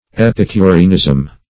Epicureanism \Ep`i*cu*re"an*ism\, n.